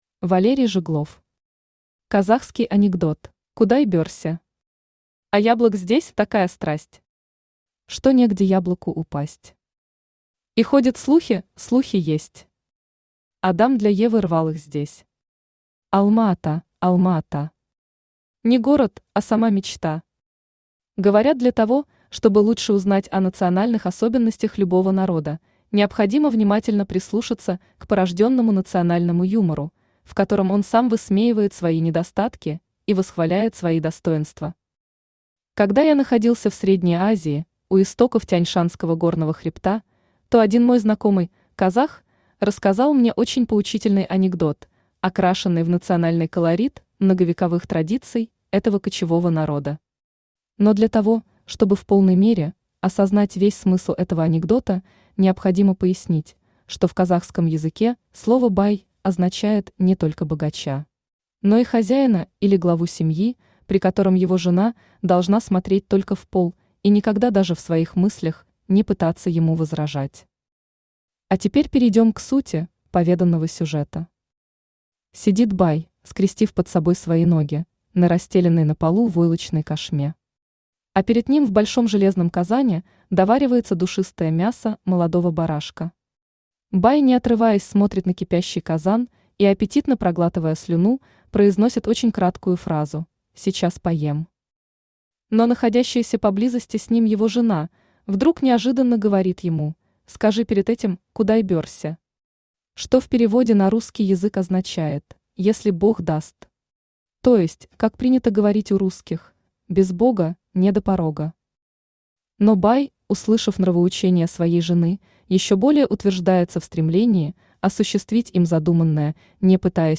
Аудиокнига Казахский анекдот | Библиотека аудиокниг
Aудиокнига Казахский анекдот Автор Валерий Жиглов Читает аудиокнигу Авточтец ЛитРес.